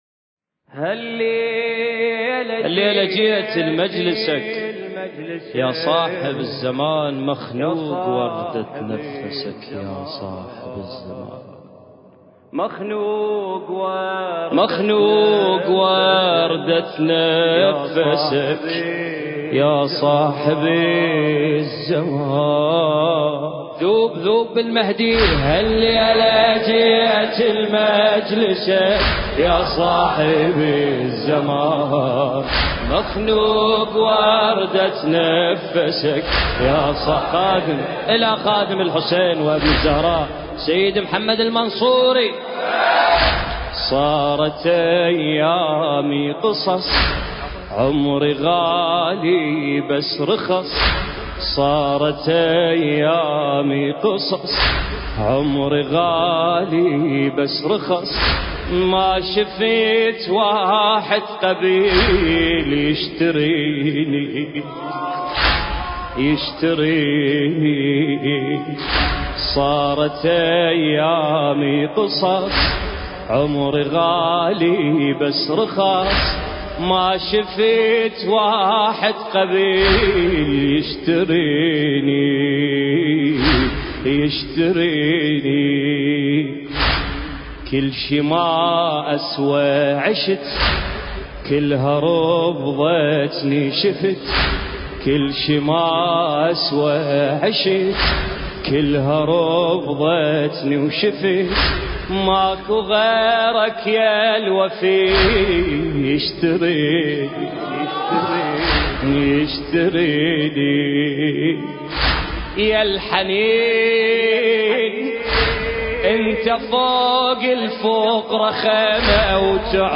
المكان: مضيف السيدة شريفة (عليها السلام) - بغداد